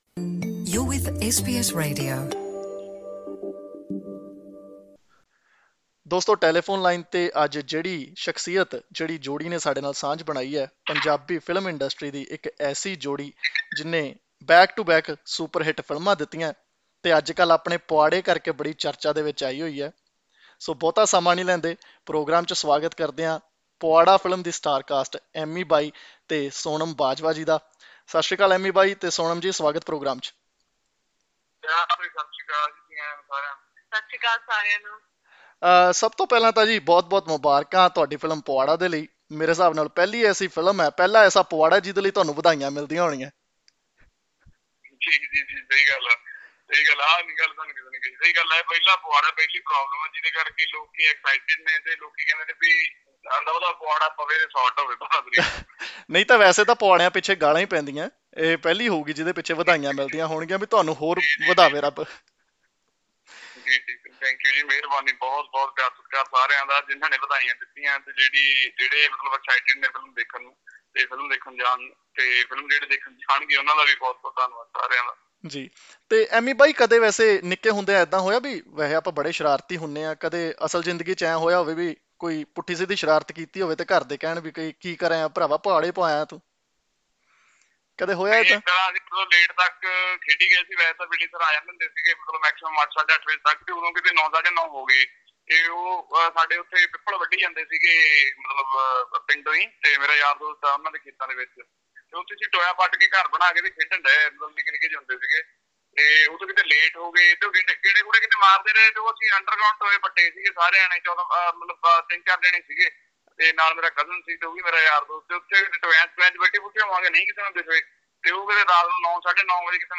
In an exclusive chat with SBS Punjabi, lead actors Ammy Virk and Sonam Bajwa talk about their journey through the making of 'Puaada' and the challenges the filmmakers faced to release this dose of humour.